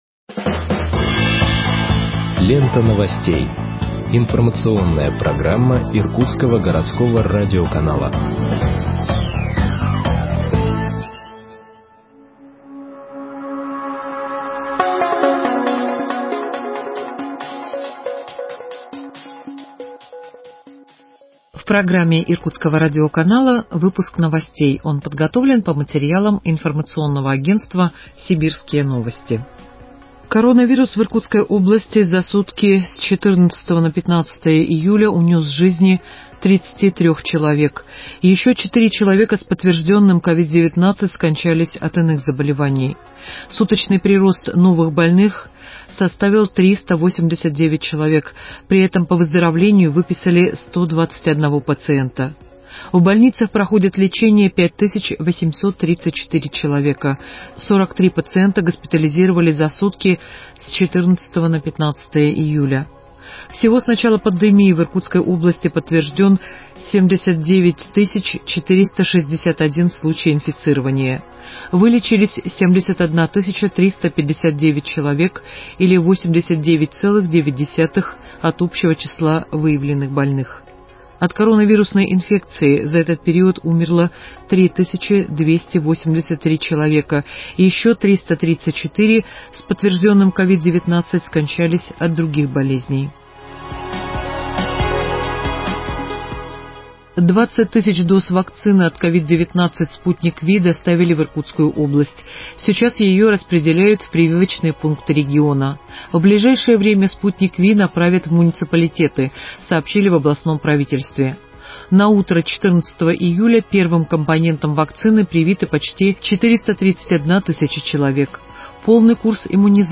Выпуск новостей от 16.07.2021 № 1